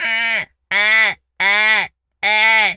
seagul_squawk_deep_01.wav